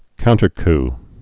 (kountər-k)